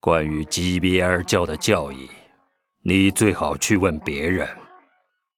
文件 文件历史 文件用途 全域文件用途 Vanjelis_tk_02.ogg （Ogg Vorbis声音文件，长度5.4秒，103 kbps，文件大小：68 KB） 源地址:地下城与勇士游戏语音 文件历史 点击某个日期/时间查看对应时刻的文件。 日期/时间 缩略图 大小 用户 备注 当前 2018年5月13日 (日) 02:57 5.4秒 （68 KB） 地下城与勇士  （ 留言 | 贡献 ） 分类:范哲利斯 分类:地下城与勇士 源地址:地下城与勇士游戏语音 您不可以覆盖此文件。